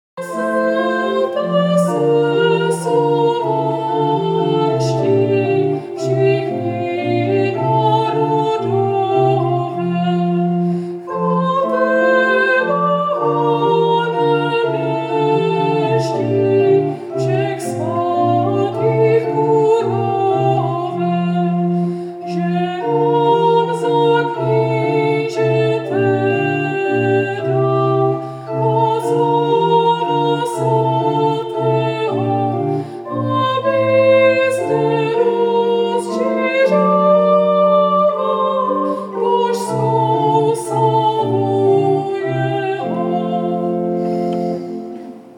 Nápěv hymnu
Hymnus_cut_45sec.mp3